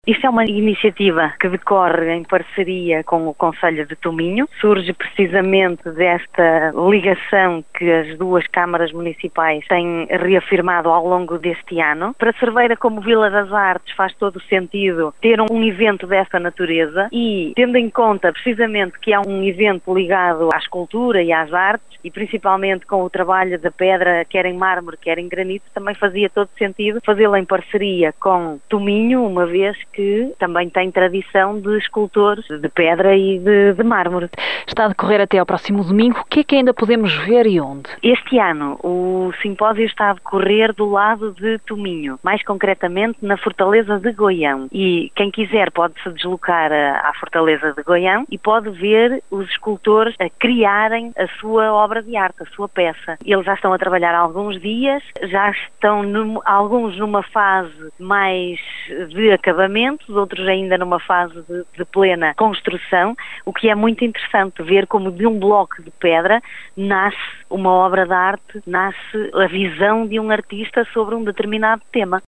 O primeiro Simpósio Internacional de Escultura do Minho, organizado pelos Concelhos de Cerveira e Tominho, no âmbito da Carta da Amizade, está a decorrer até este Domingo. A vice-presidente da autarquia portuguesa, Aurora Viães, revela pormenores sobre esta iniciativa: